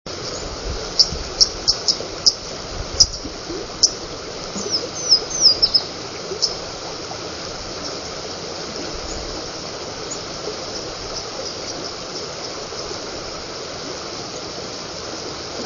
Louisiana Waterthrush
Upper Van Campen's Glen, Delaware Water Gap, 4/20/03, same pair of Waterthrush flying back and forth making a "zupp" sound, a high "seep"and a partial song then "zupping" as they speedily fly upstream (62kb).